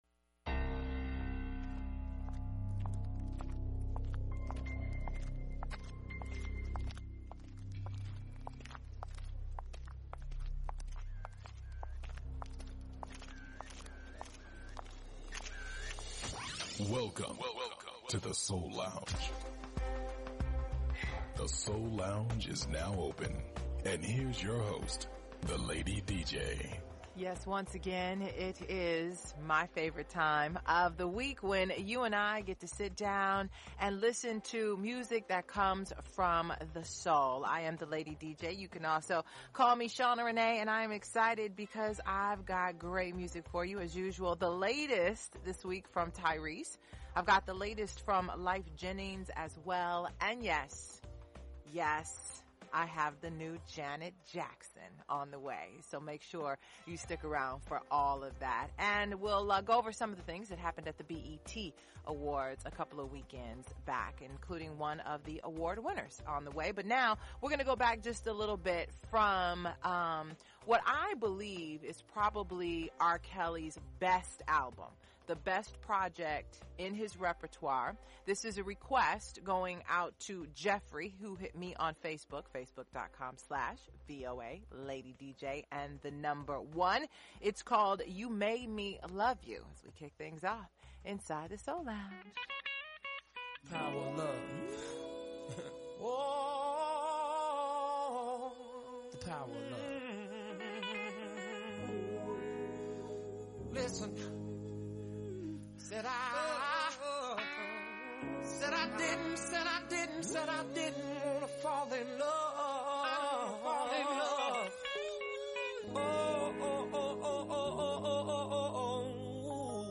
music, interviews and performances
Neo-Soul
conscious Hip-Hop
Classic Soul